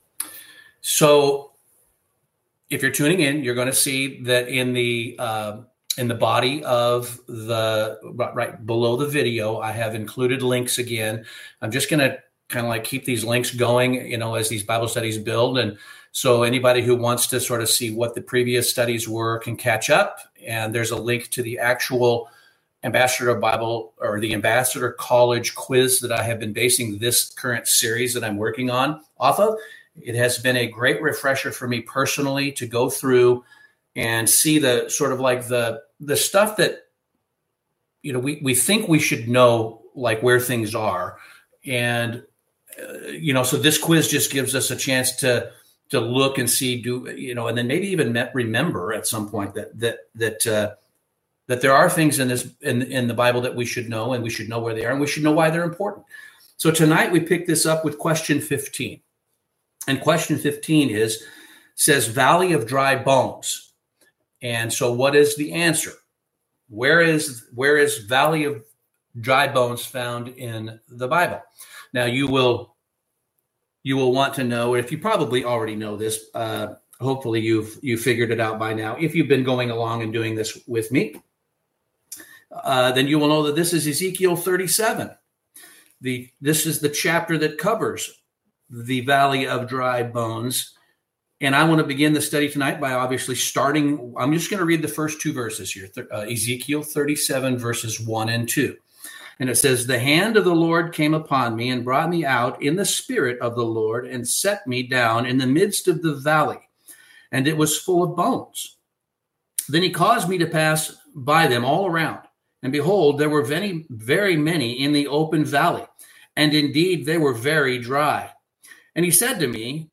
NW Bible Study - Bible Quiz #15 - Valley of Dry Bones